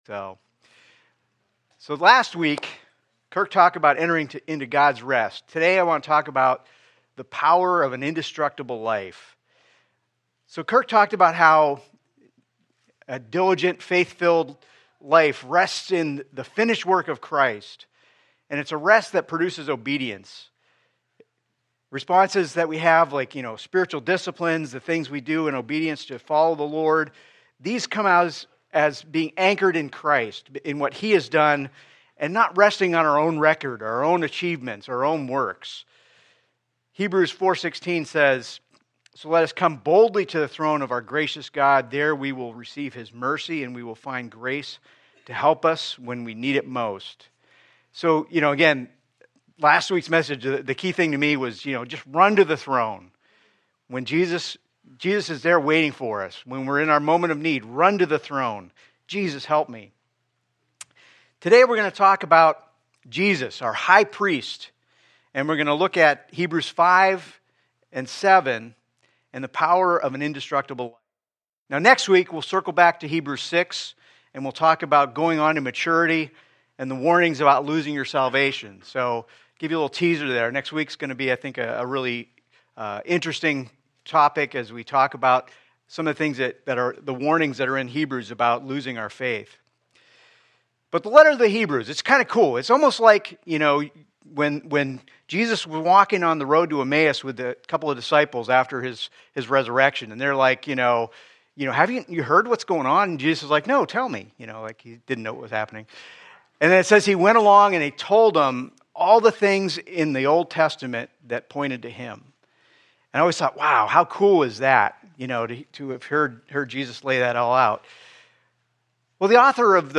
From Series: "Sunday Morning Service"